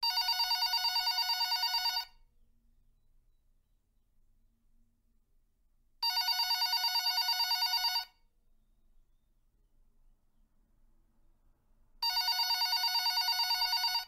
Klingelton Telefon 90er
Kategorien Telefon